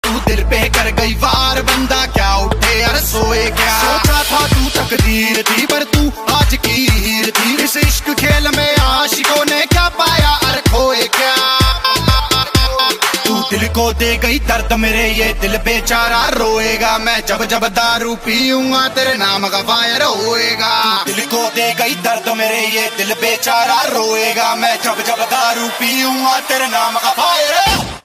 Dance - Electronics